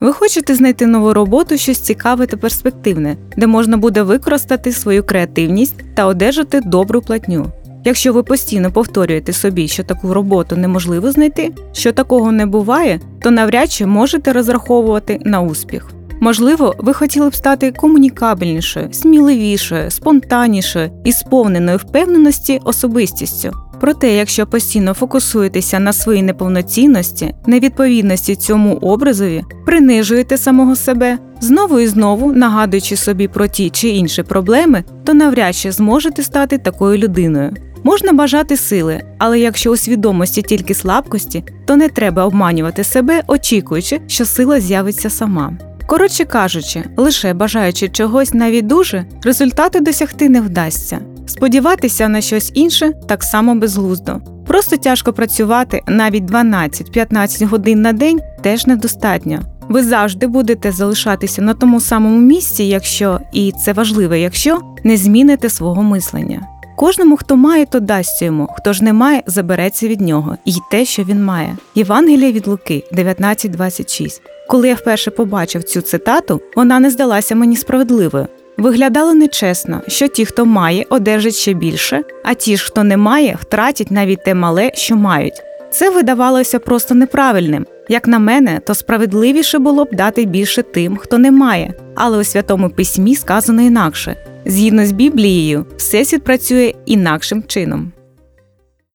Жіноча